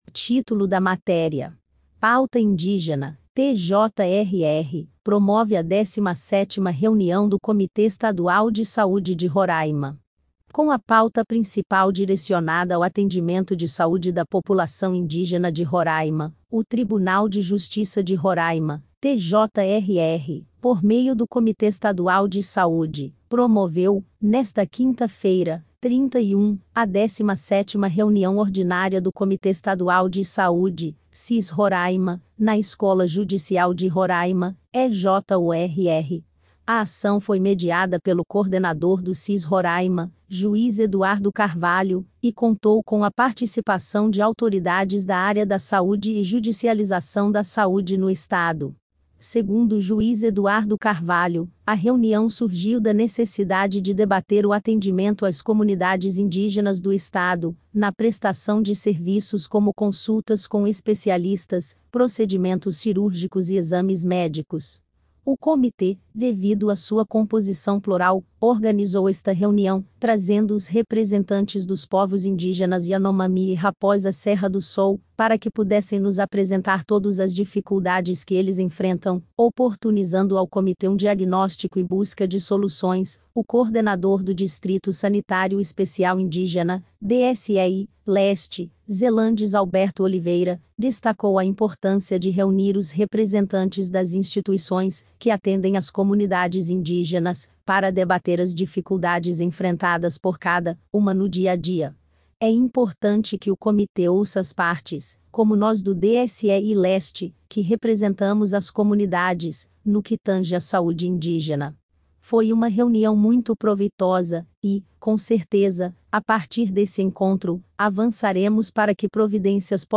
PAUTA_INDIGENA_IA.mp3